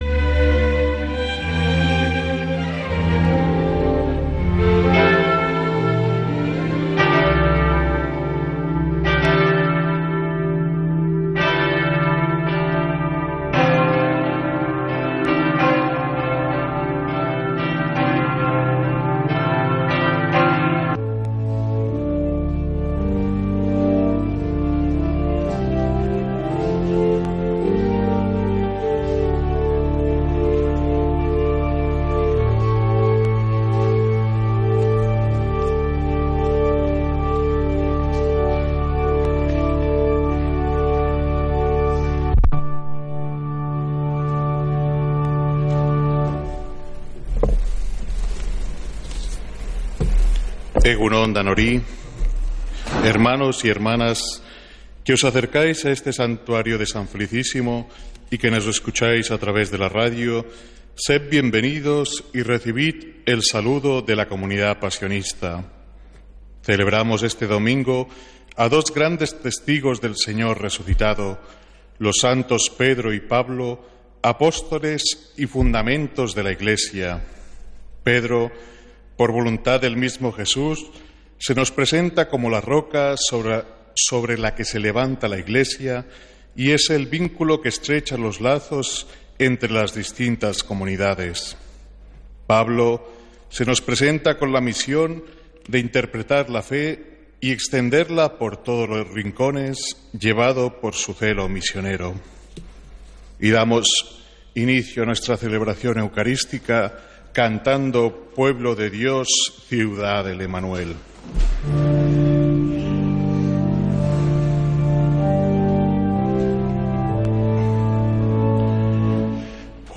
Santa Misa desde San Felicísimo en Deusto, domingo 29 de junio